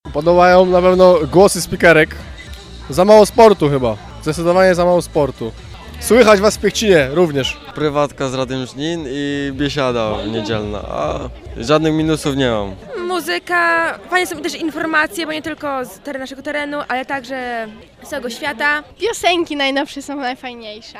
Wtedy też pytaliśmy mieszkańców co podoba lub nie podoba im się w radiu.